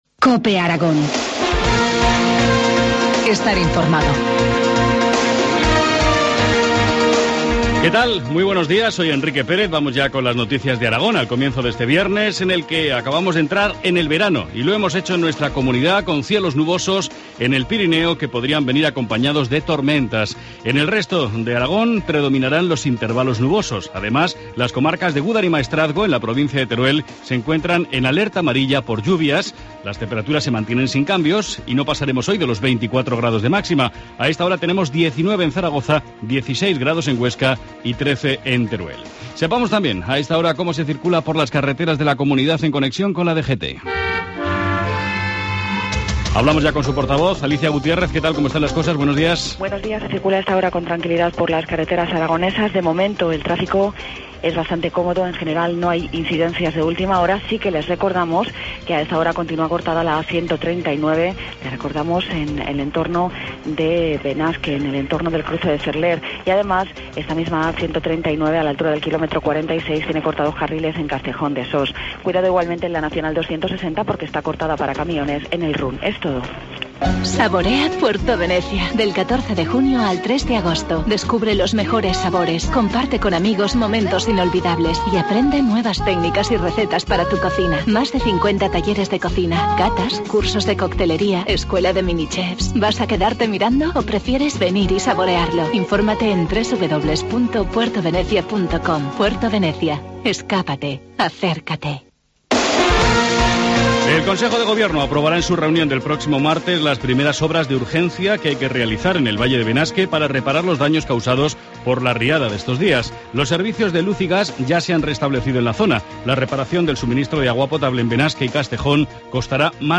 Informativo matinal, viernes 21 de junio, 7.25 horas